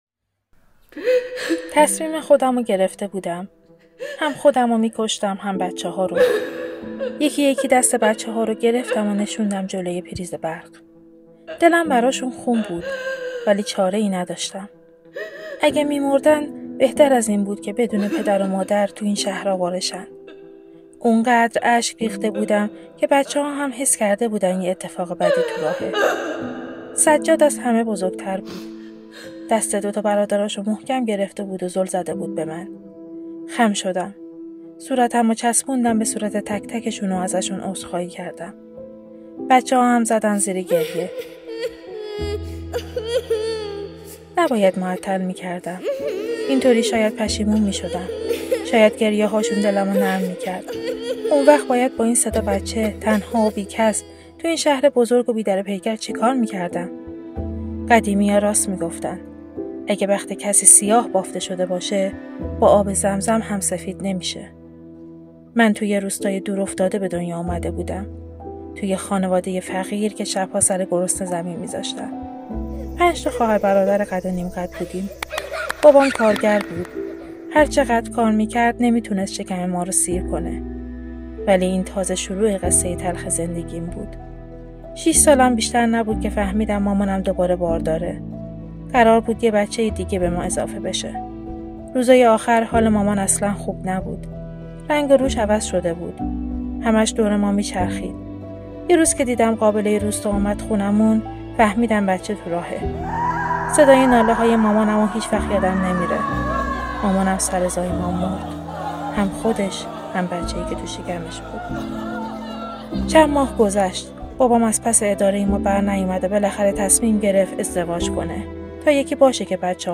• در ادامه صوت این پادکست داستانی را می شنویم: